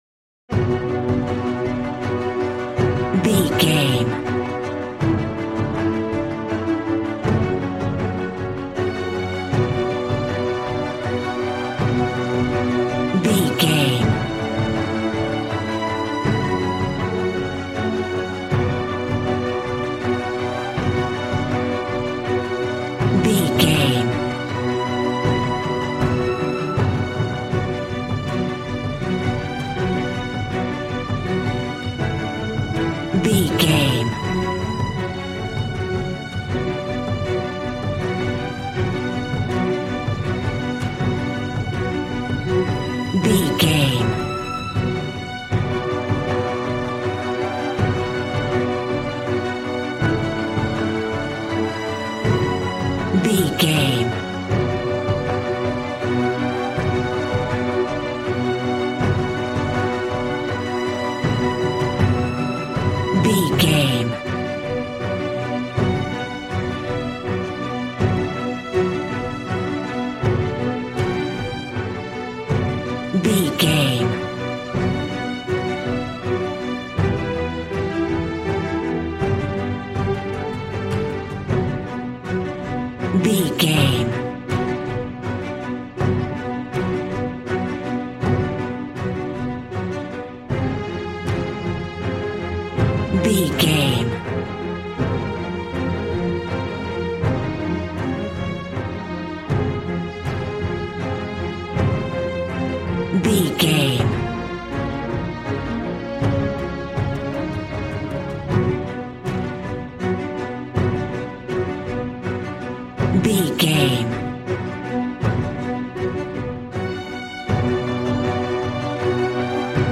Aeolian/Minor
suspense
piano
synthesiser